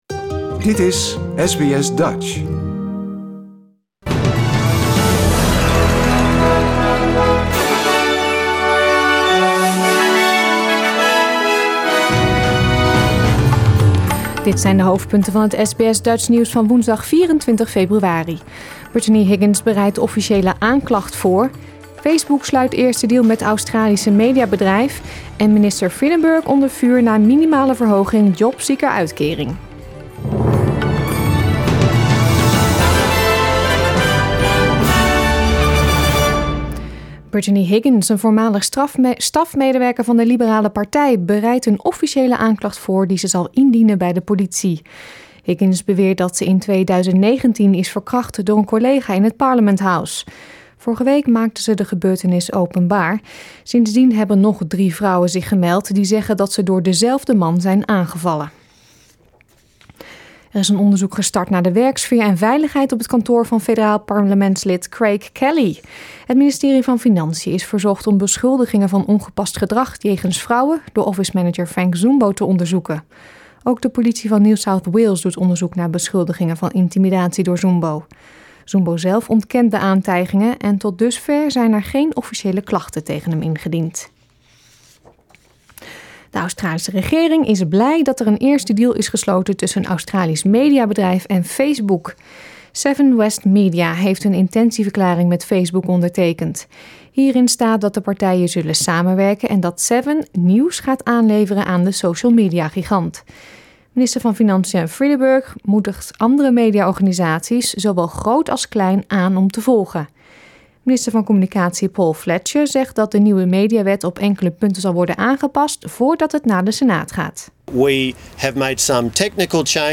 Nederlands/Australisch SBS Dutch nieuwsbulletin woensdag 24 februari 2021